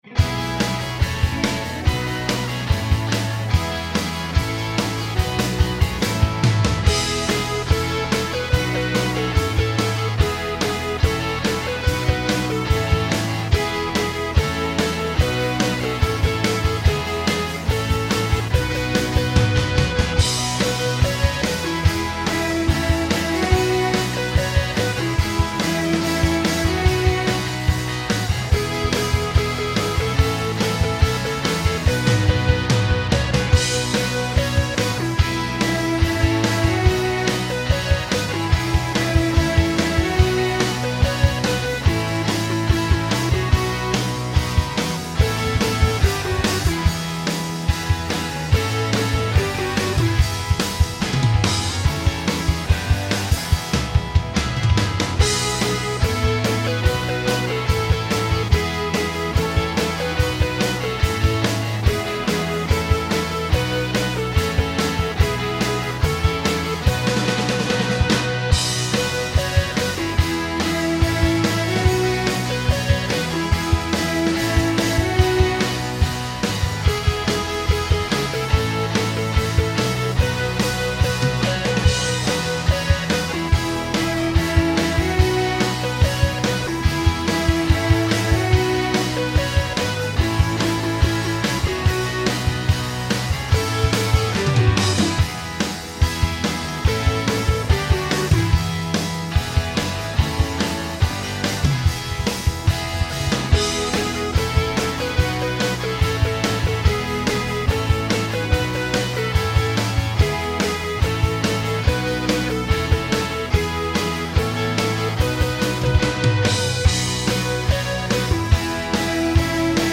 rousing rock song